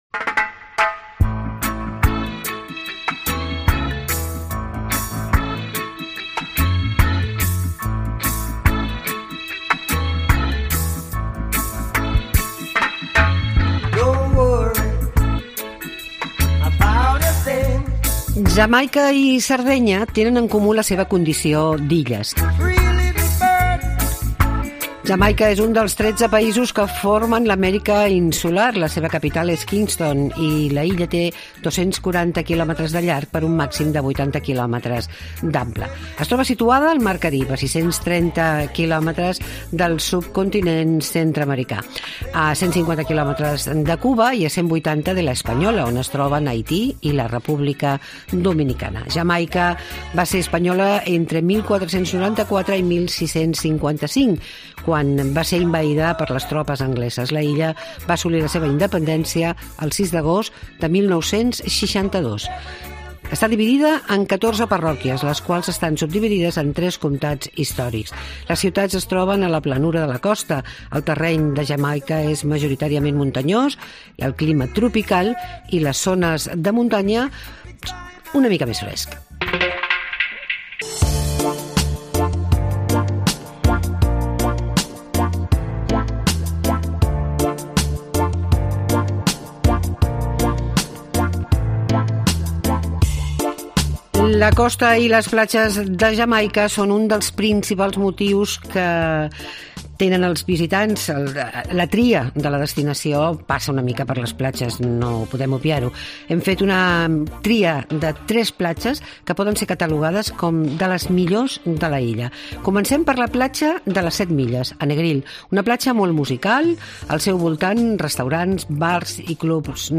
Tots els caps de setmana fem tres hores de ràdio pensades per aquells que els agrada passar-ho bé en el seu temps d'oci, on?